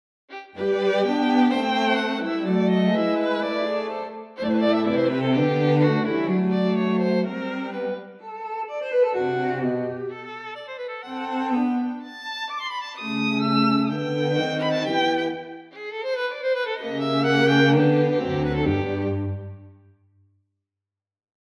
in spite of the rawness of sounds...
StringQtetKH-Swam.mp3